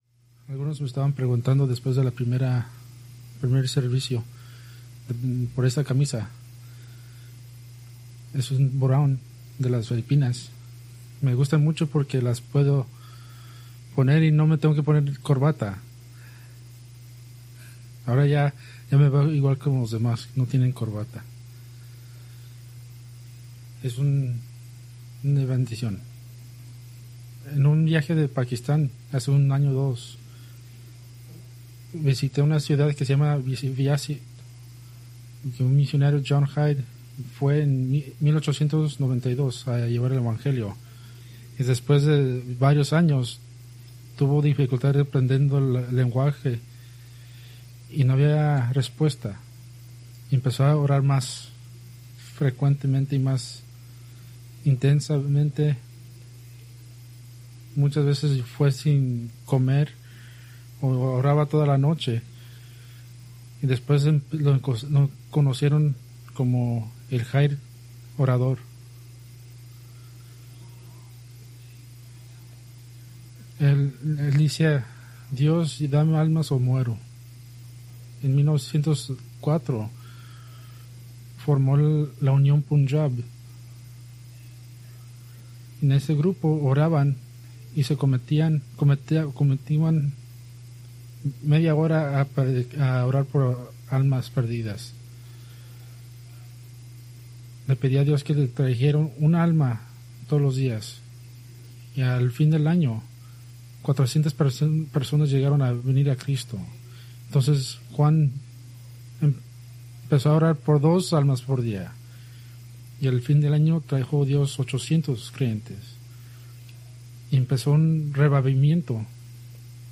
Preached January 11, 2026 from John 4:1-42